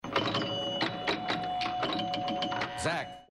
It's the arcade precursor to video games heard